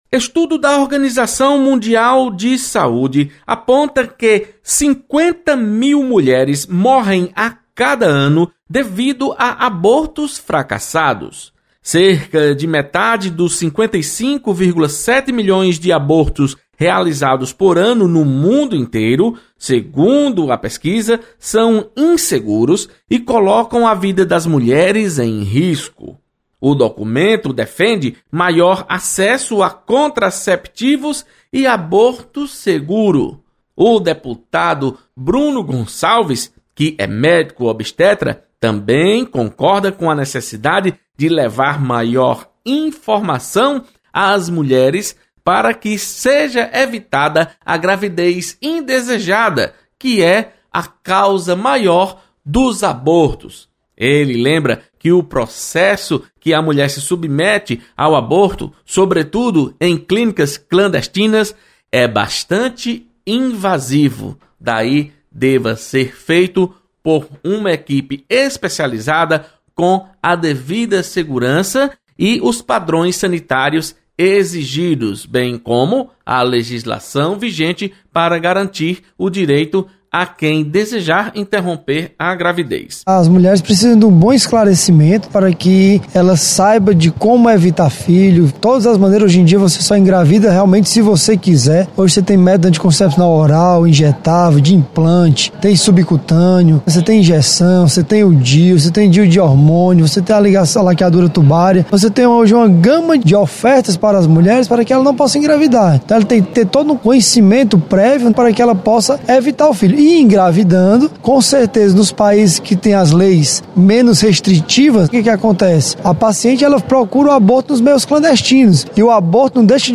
Deputado Bruno Gonçalves comenta pesquisa da OMS apontando que metade dos abortos realizados no mundo coloca vida de mulheres em risco.